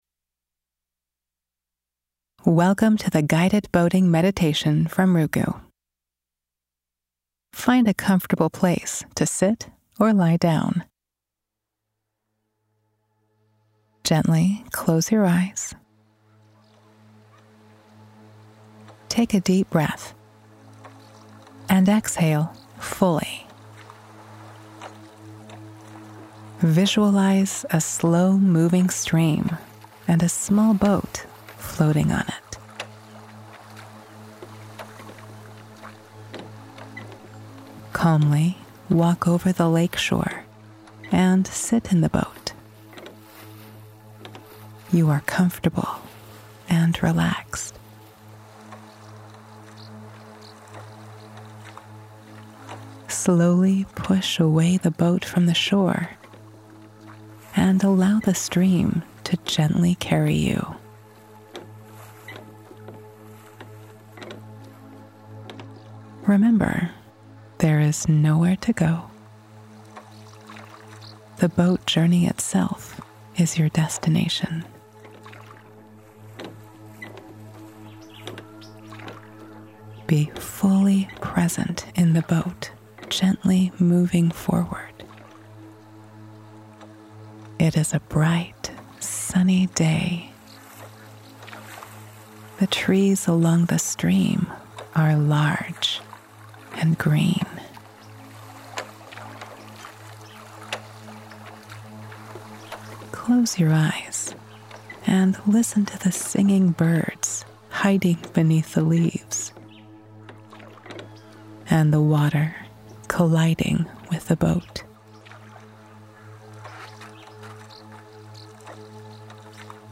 Meditate – Guided Imagery Boating